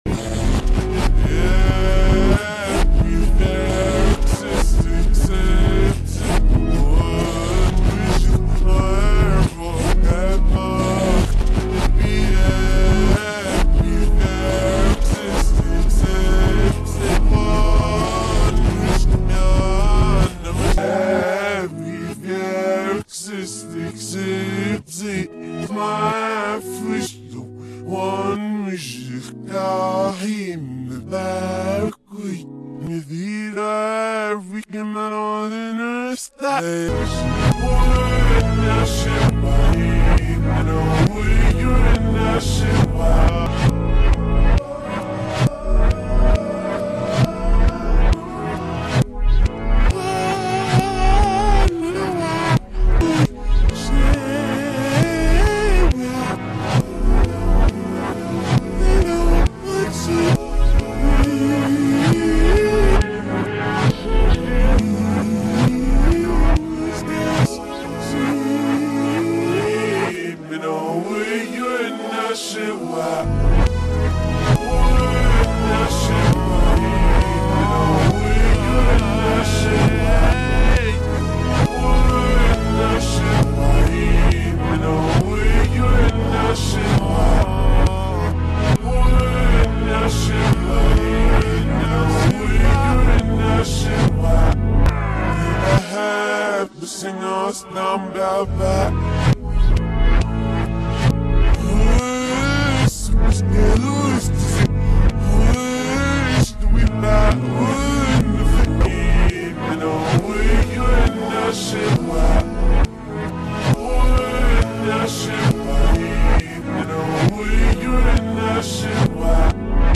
in reverse pretty odd..